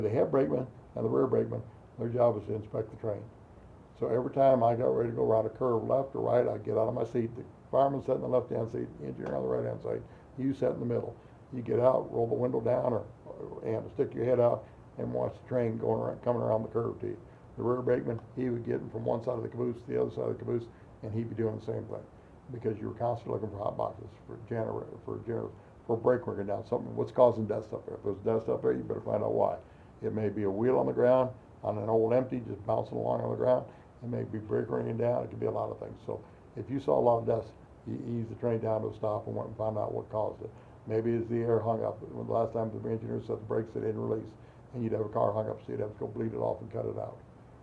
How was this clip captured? Archives of Appalachia